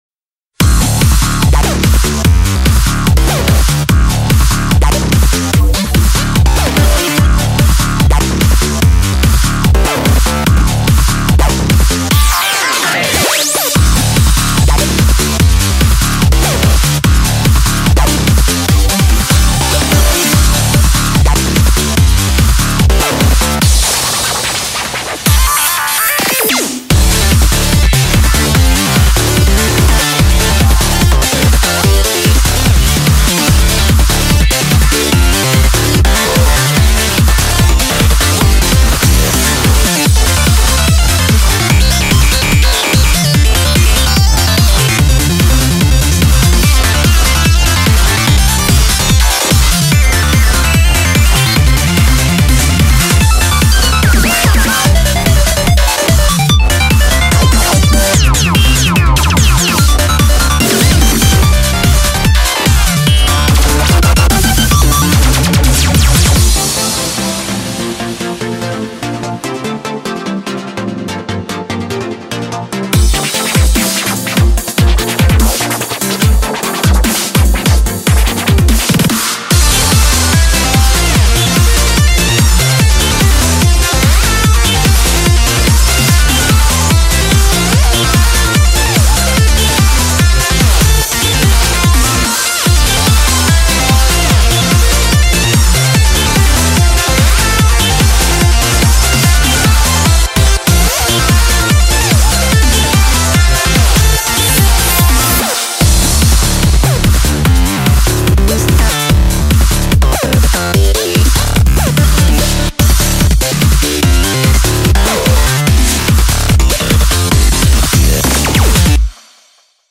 BPM73-146
Audio QualityPerfect (High Quality)
Commentaires[SYNTHETIC EDM]